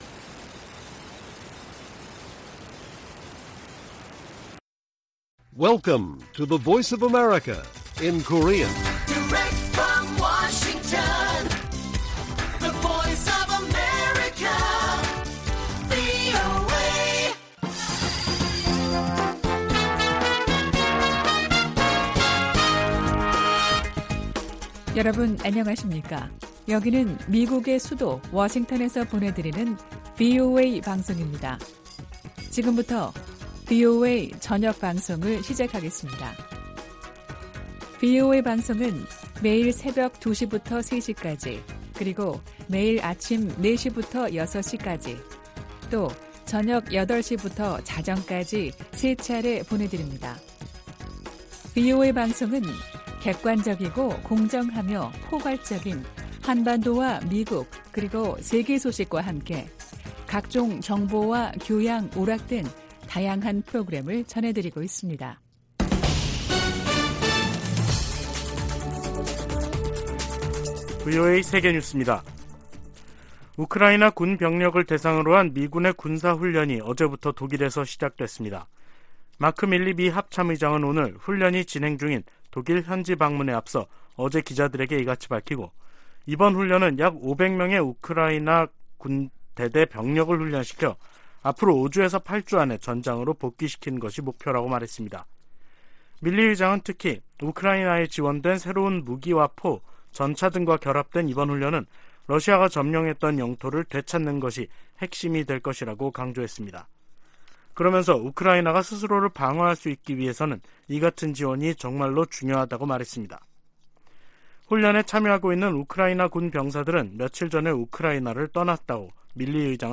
VOA 한국어 간판 뉴스 프로그램 '뉴스 투데이', 2023년 1월 16일 1부 방송입니다. 조 바이든 미국 대통령과 기시다 후미오 일본 총리가 워싱턴에서 열린 정상회담에서 한반도 비핵화와 북한의 납치 문제 등을 논의했습니다. 지난해 미국과 한국 정부의 대북 공조가 더욱 강화됐다고 미국 의회조사국이 평가했습니다.